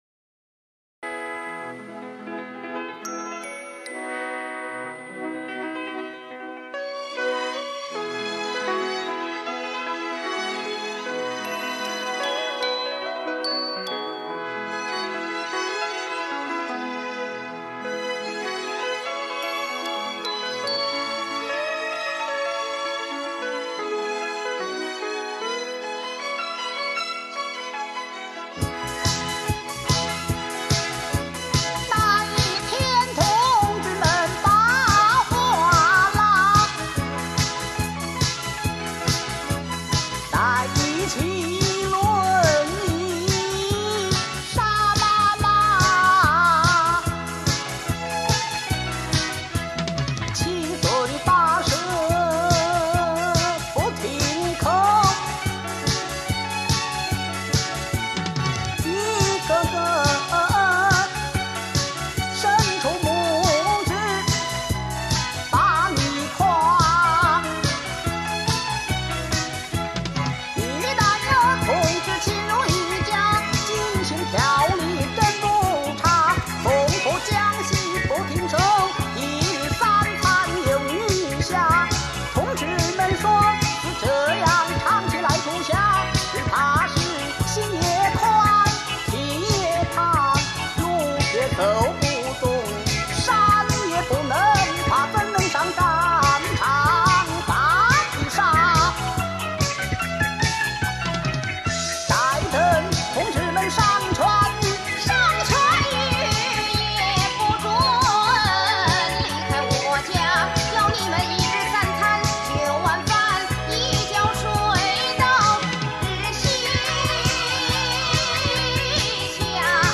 京腔京韵尽显荟萃风范，电声伴奏增添时代特色，绝版经典专辑 值得永久珍藏